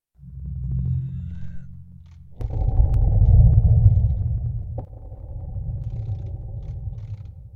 Ambient9.ogg